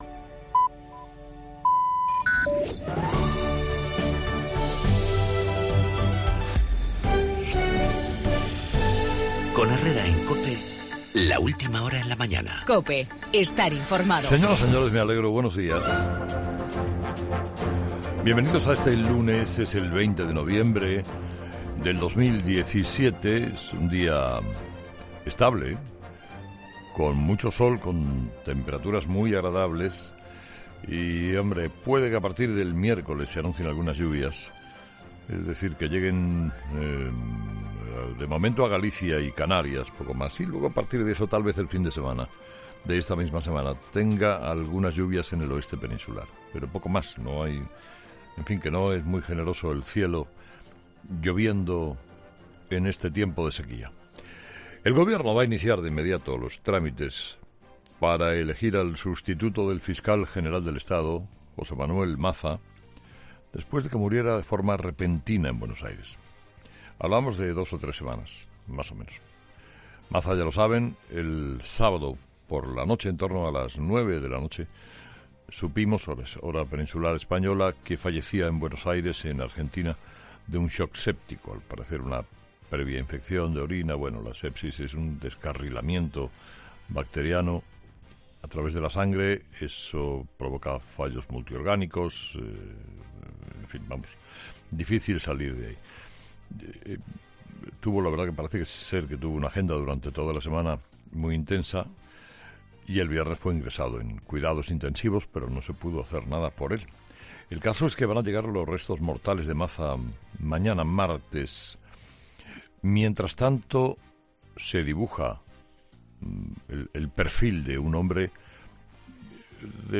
El fallecimiento del fiscal general del Estado, en el monólogo de Carlos Herrera